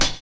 Closed Hats
Wu-RZA-Hat 69.wav